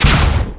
fire_plasma1.wav